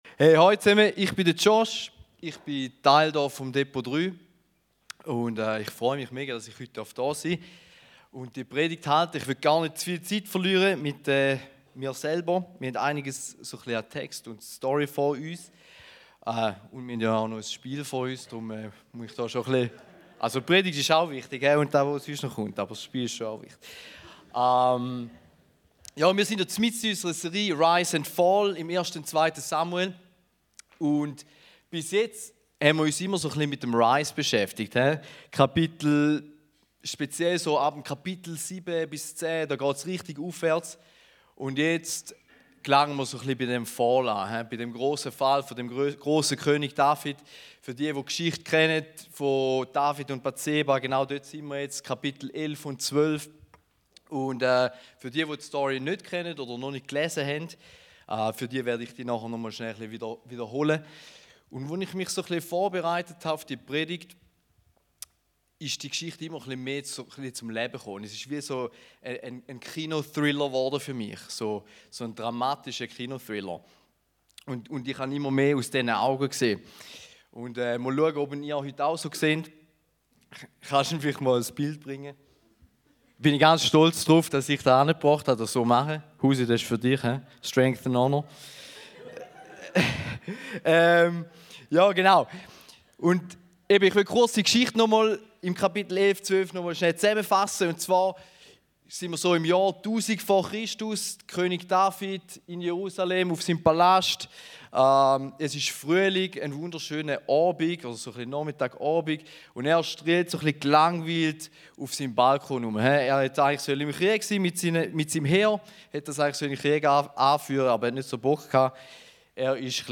Eine predigt aus der serie "RISE & FALL."